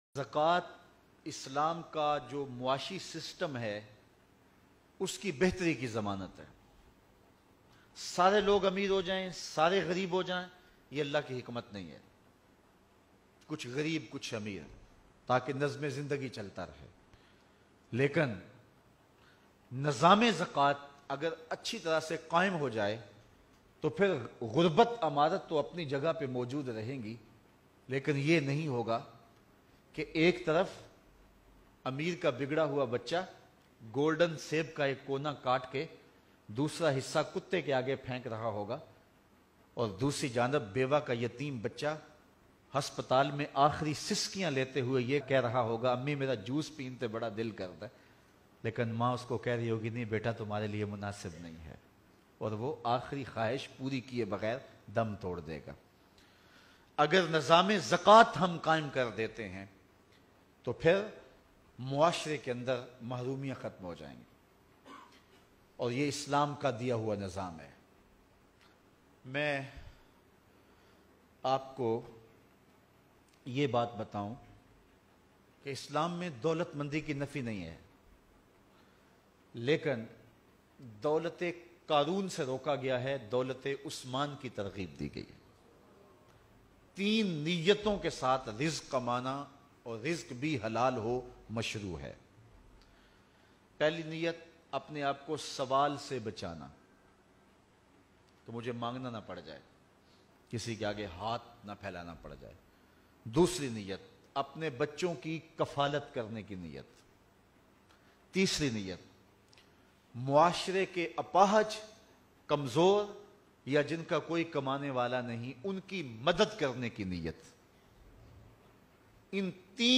Bayan